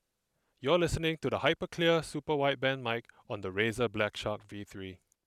Razer Orange Tactile Mechanical Switch Gen-3
All typing test sound clips are recorded on a stock Razer BlackWidow V4 Tenkeyless HyperSpeed with no modifications.